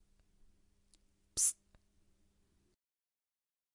描述：记录'psst'的人的录音
Tag: SFX 安静 OWI